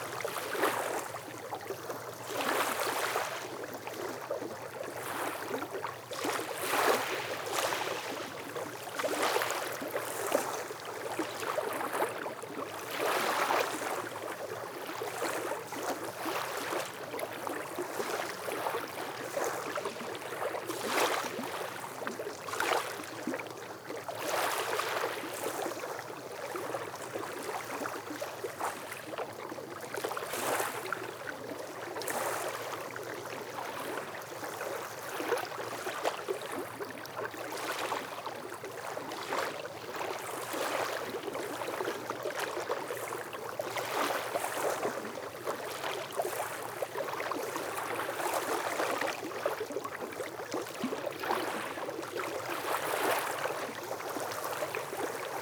WaterLapping.ogg